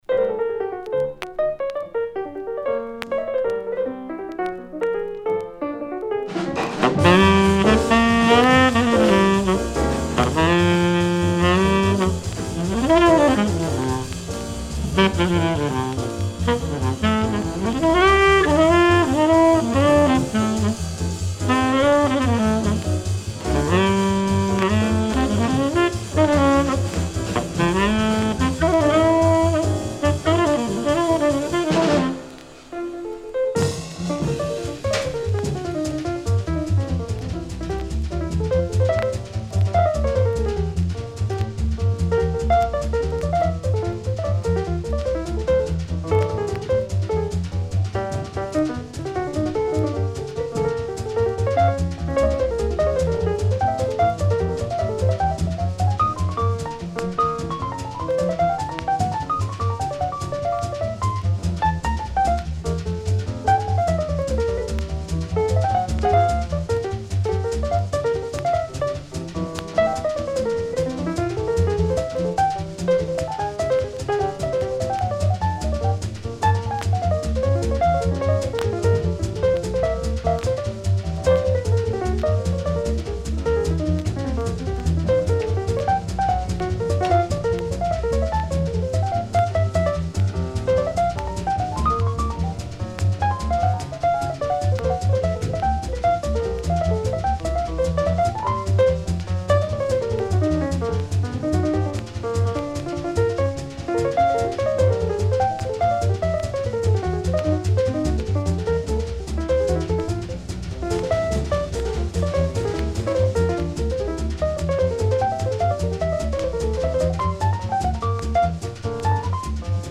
Original 1956 pressing (mono)